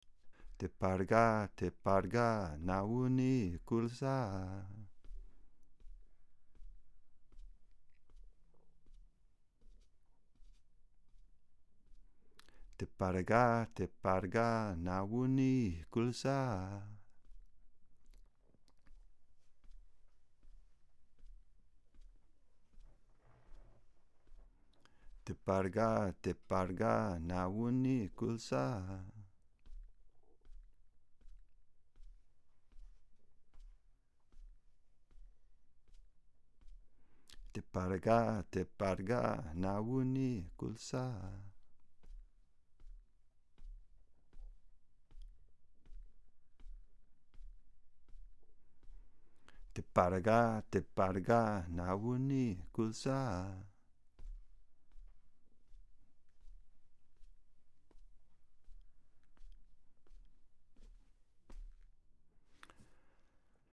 lunga drum language
Talking drums Dagomba drumming Ghanian music African drumming